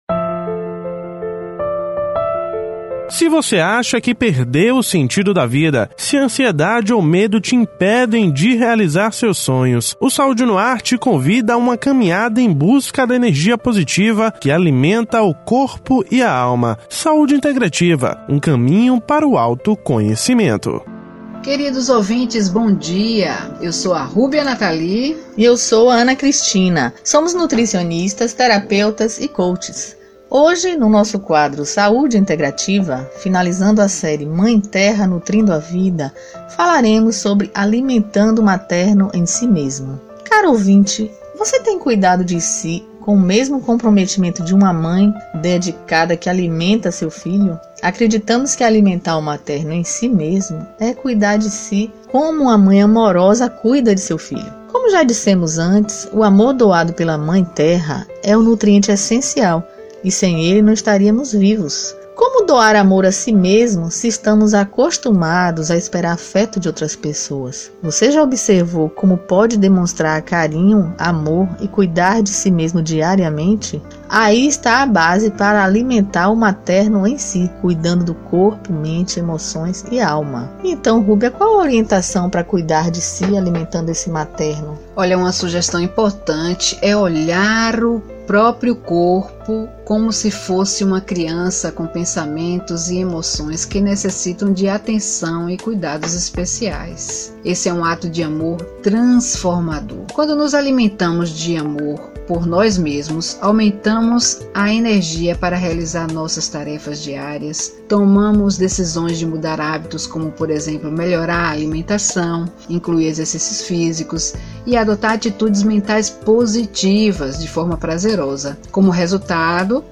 Ouça o comentário completo das especialistas e saiba mais sobre os benefícios de estarmos atentos às nossas necessidades, no áudio abaixo: